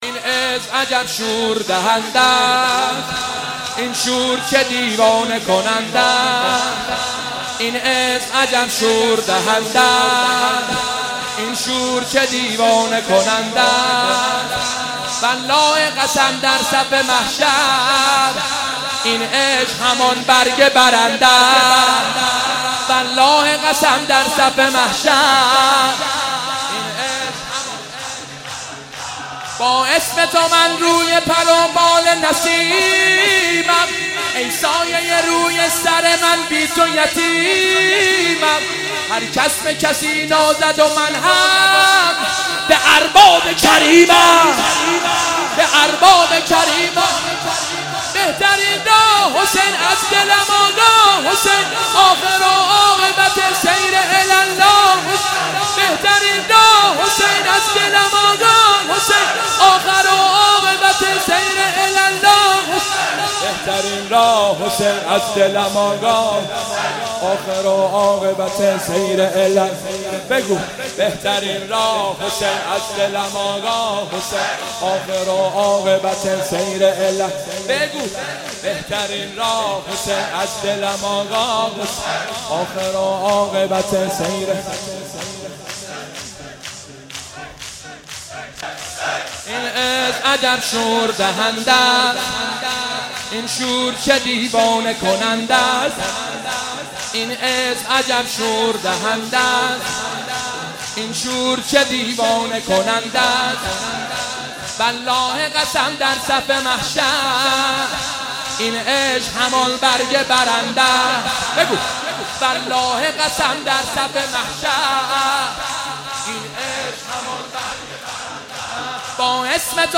شب 7 محرم95
شور جدید و زیبا